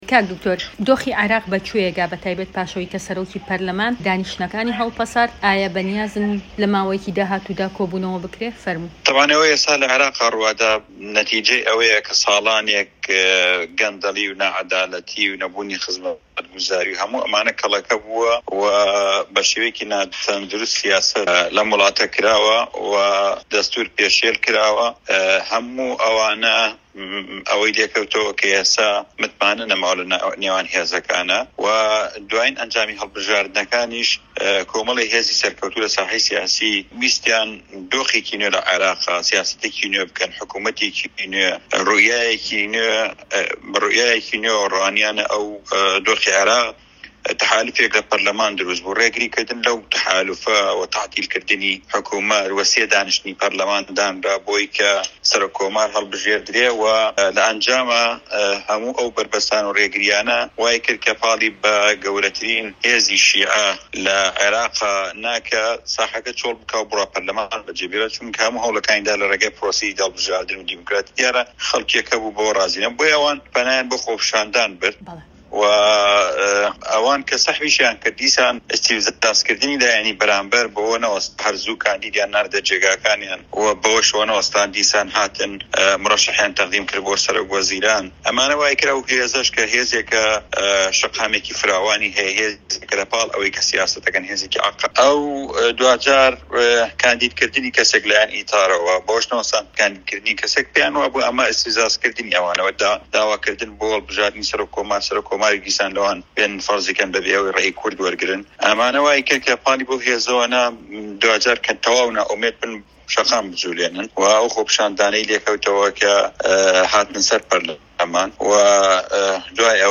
وتووێژ لەگەڵ شاخەوان عەبدوڵا